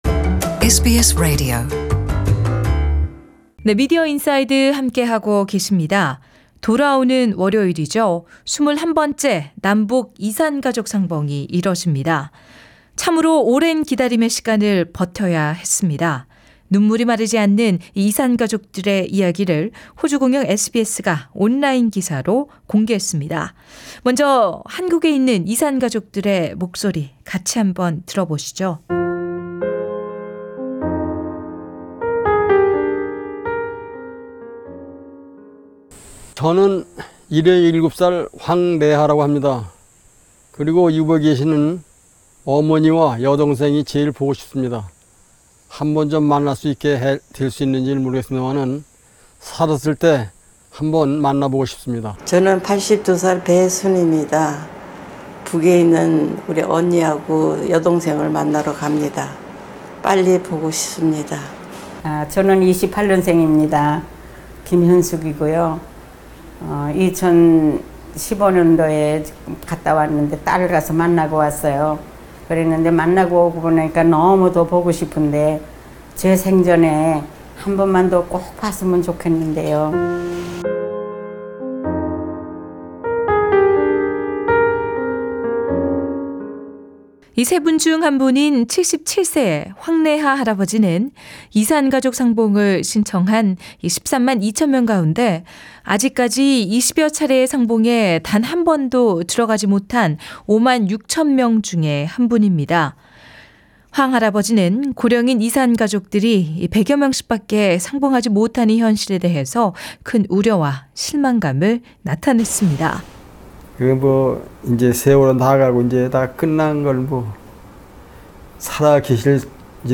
이백순 대사와의 전체 인터뷰는 상단의 팟캐스트를 통해 들으실 수 있습니다.